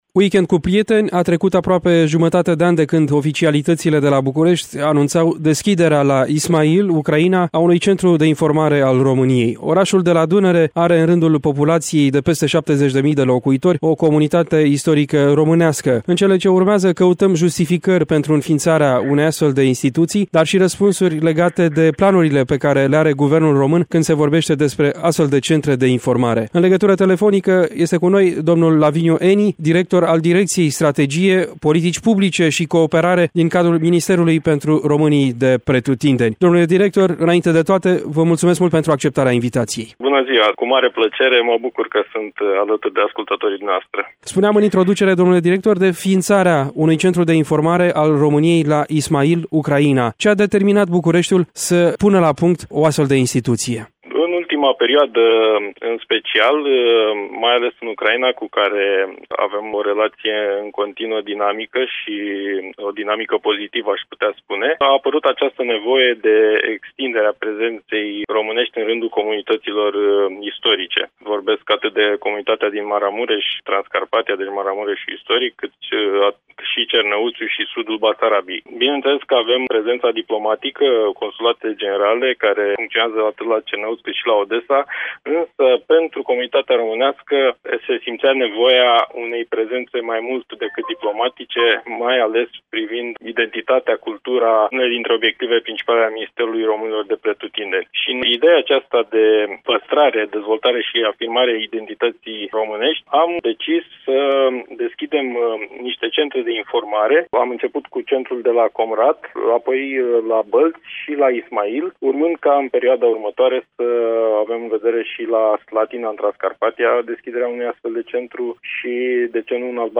Acelaşi interlocutor a făcut referiri şi la situaţia şcolilor cu predare în limba română.